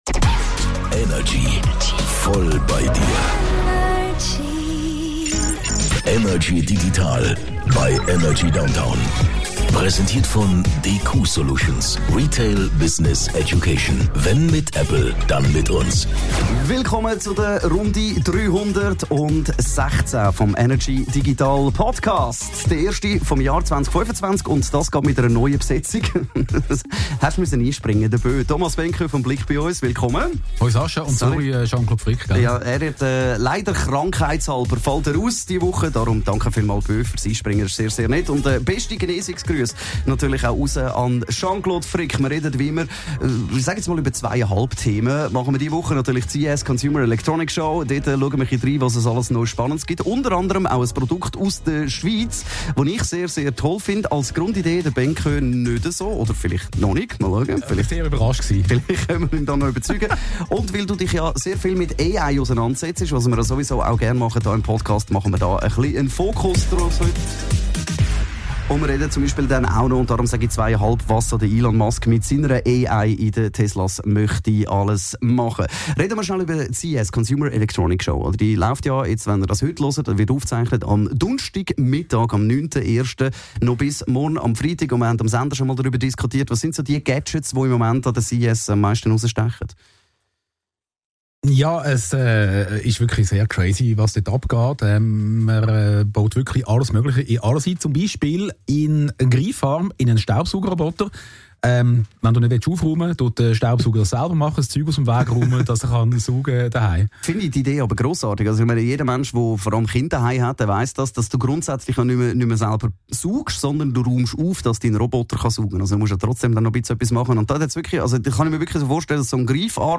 im Energy Studio